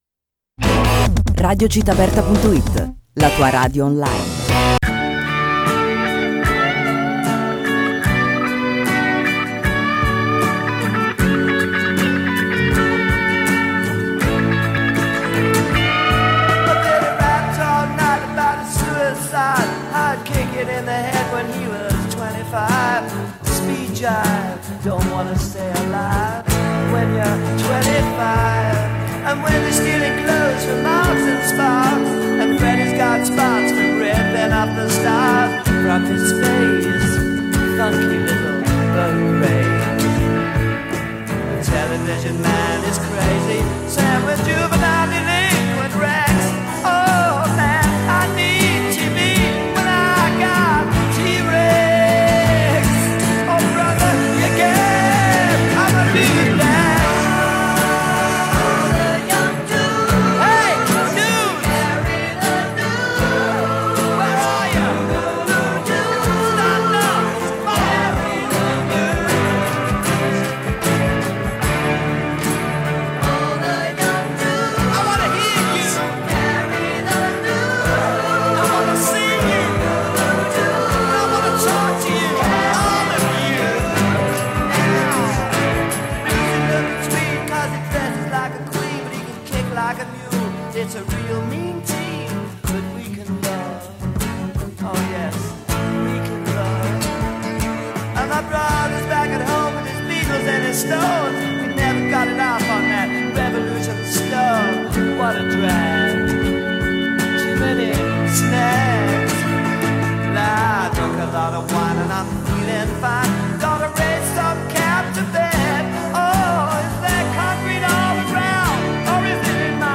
Nel corso dell’intervista abbiamo ascoltato: